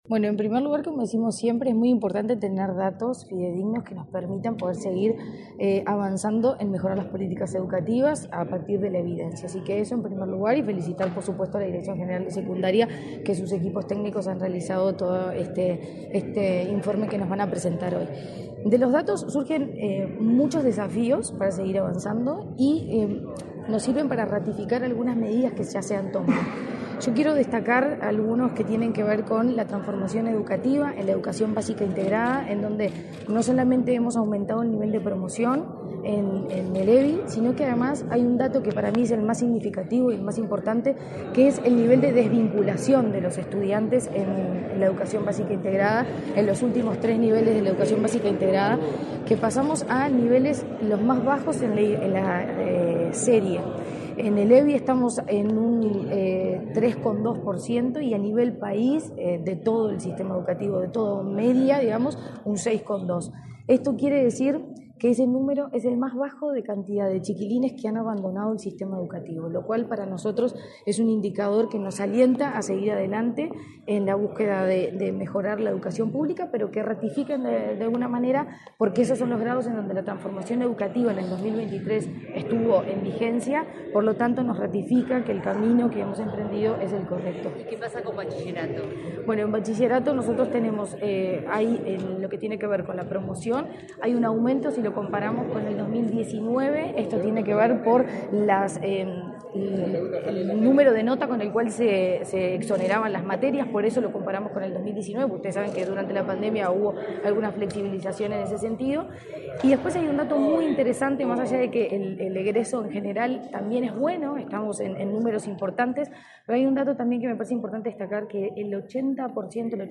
Declaraciones de la presidenta de ANEP, Virginia Cáceres
Declaraciones de la presidenta de ANEP, Virginia Cáceres 20/08/2024 Compartir Facebook X Copiar enlace WhatsApp LinkedIn Este martes 20, la presidenta de la Administración Nacional de Educación Pública (ANEP), Virginia Cáceres, dialogó con la prensa, antes de participar en el acto de presentación de los datos del Monitor Educativo Liceal.